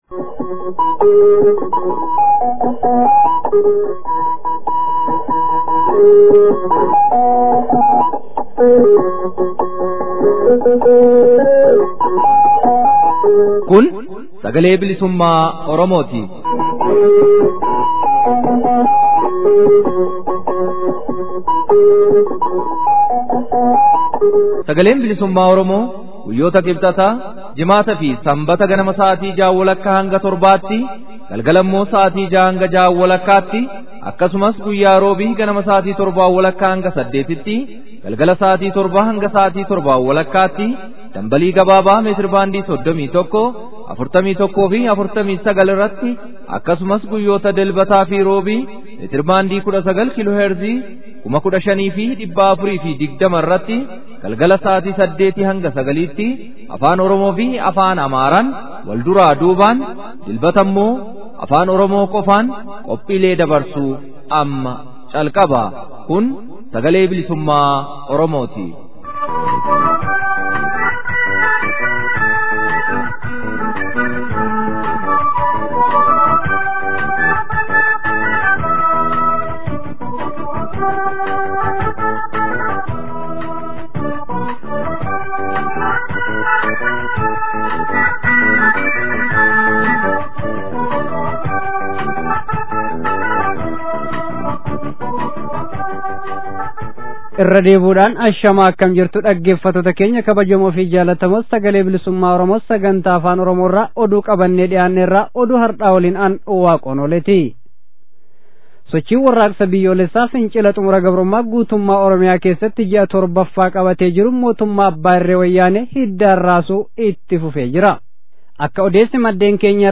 SBO Waxabajjii 22,2016. Oduu, Gaaffii fi deebii barattoota Oromoo dhaabbilee barnootaa olaanoo fi sadarkaa adda addaa kanneen FXG keessatti hirmaataa turan waliin goone kutaa 2ffaa fi SBO Sagantaa Afaan Amaaraa